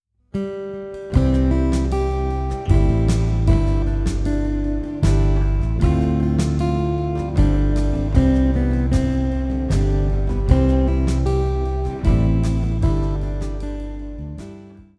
guitar tabs melody preview